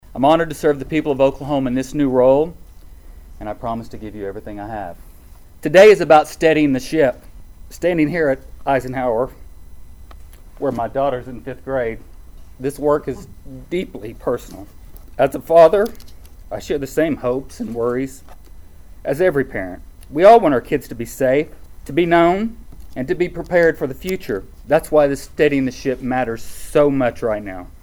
In an emotional statement, Fields says the appointment as state superintendent is one that is